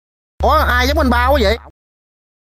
Thể loại: Câu nói Viral Việt Nam
Description: Đây là một tiếng động độc đáo, kiểu như âm thanh meme thường thấy trong các clip troll, video hài hoặc video viral ngắn.
cau-noi-viral-ua-ai-giong-anh-ba-qua-vay-www_tiengdong_com.mp3